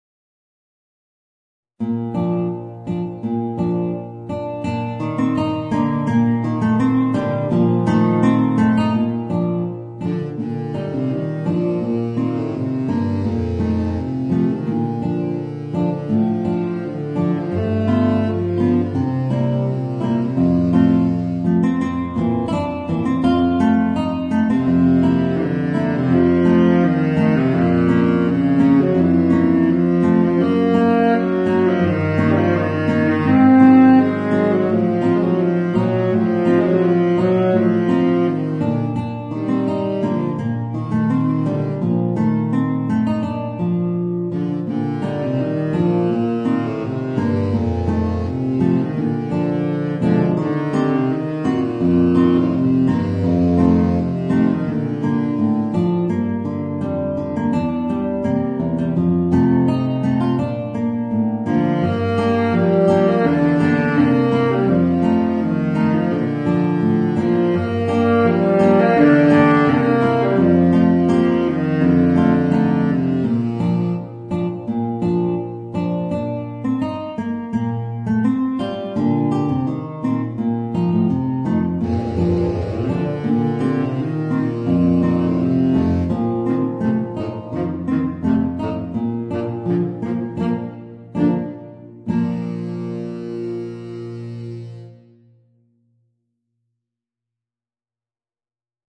Voicing: Baritone Saxophone and Guitar